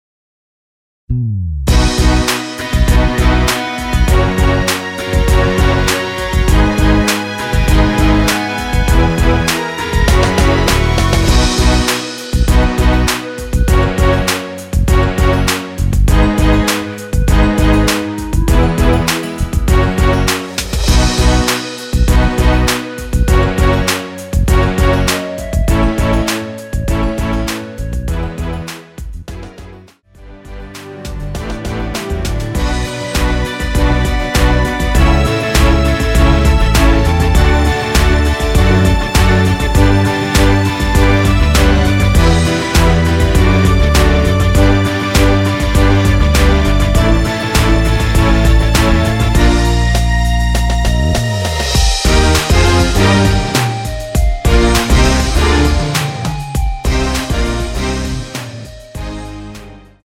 원키 멜로디 포함된 MR입니다.(미리듣기 참조)
앞부분30초, 뒷부분30초씩 편집해서 올려 드리고 있습니다.
중간에 음이 끈어지고 다시 나오는 이유는